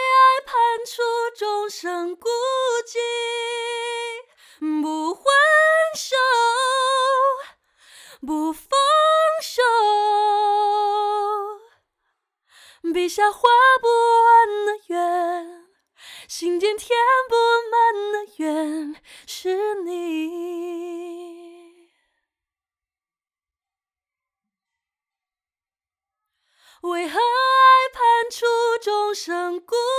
干声试听